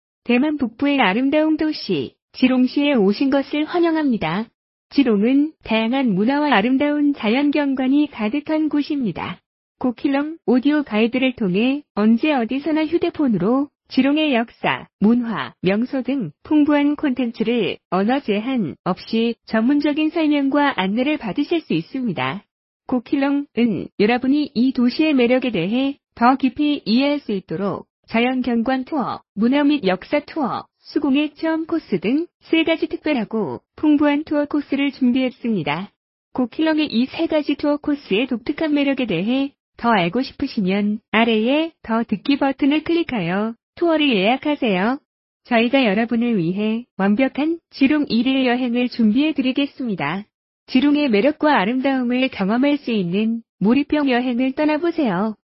GO KEELUNG음성 안내 투어로 기흥을 탐험하세요.